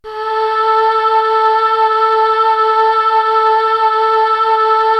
Index of /90_sSampleCDs/Best Service ProSamples vol.55 - Retro Sampler [AKAI] 1CD/Partition C/CHOIR